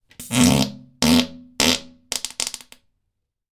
small fart
an awesome fart